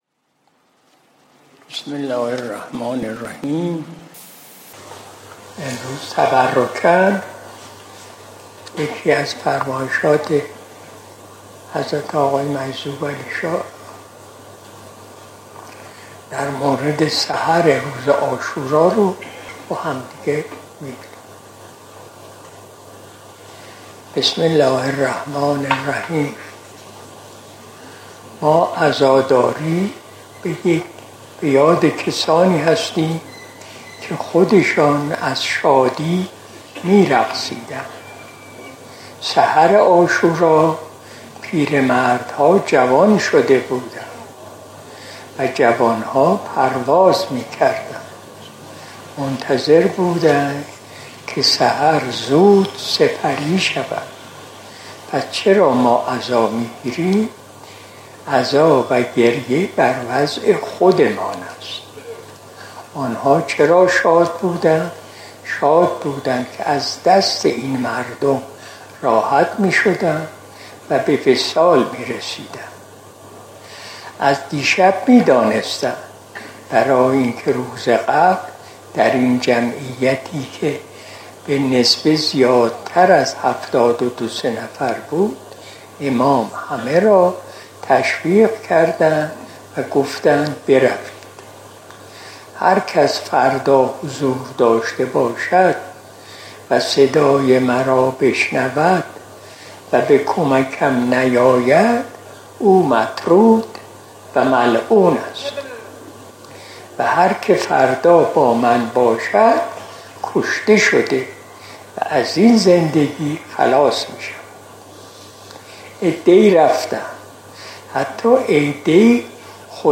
قرائت متن فرمایش حضرت آقای حاج دکتر نورعلی تابنده «مجذوبعلیشاه» طاب‌ثراه در سحر عاشورا ۱۴۳۰ قمری (۱۸ دی ۱۳۸۷ شمسی)
سحر جمعه ۶ مرداد ۱۴۰۱ شمسی – سحر عاشورا ۱۴۴۵ قمری